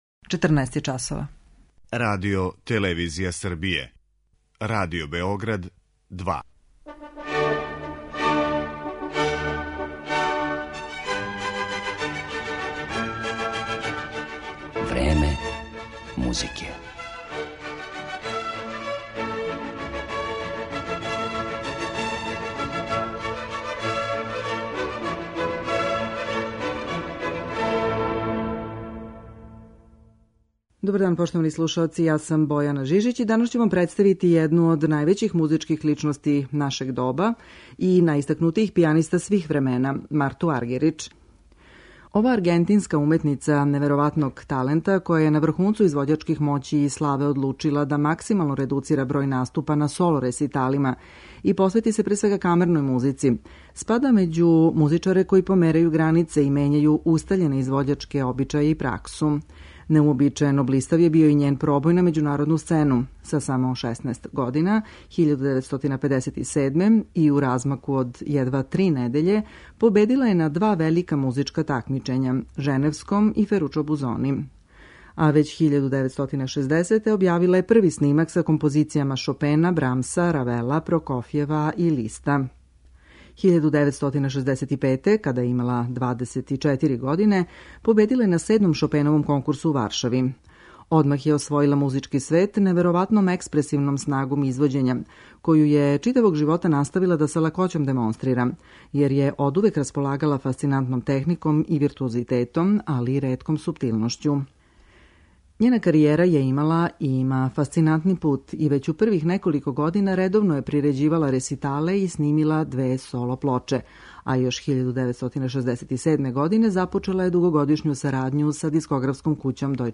Слушаћете је и као солисту и у сарадњи са другим музичарима, а изводиће дела Шопена, Бетовена, Франка, Прокофјева и Листа.